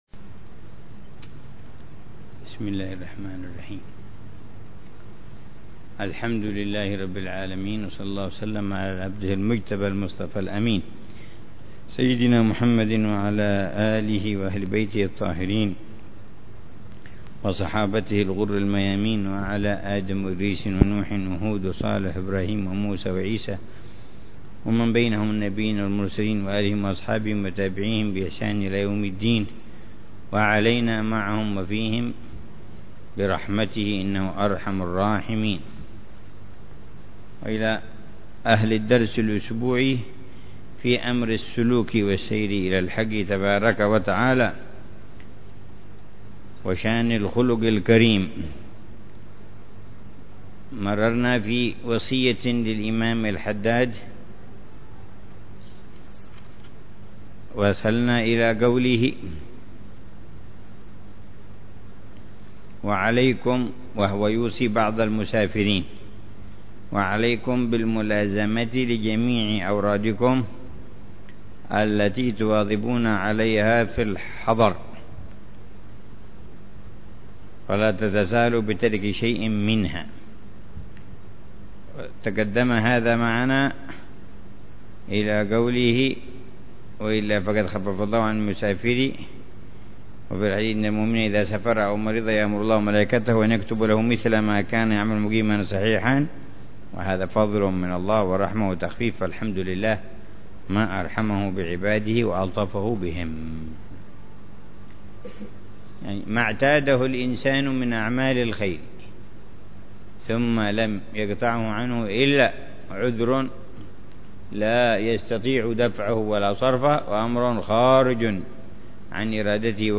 درس أسبوعي يلقيه الحبيب عمر بن حفيظ في كتاب الوصايا النافعة للإمام عبد الله بن علوي الحداد يتحدث عن مسائل مهمة في تزكية النفس وإصلاح القلب وطه